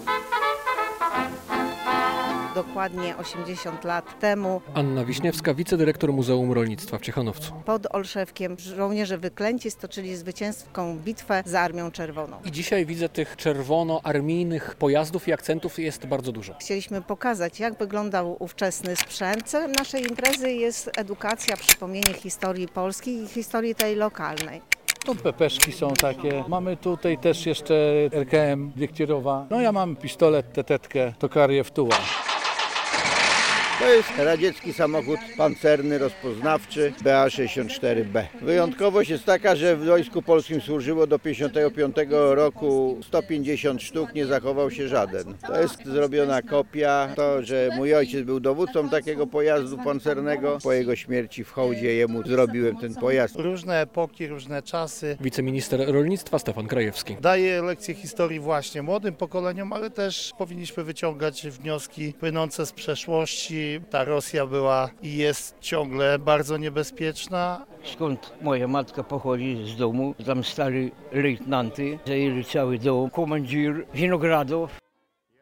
Rekonstruktorzy i pasjonaci historii na 15. Zajeździe Wysokomazowieckim - relacja